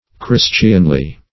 Christianly \Chris"tian*ly\, a.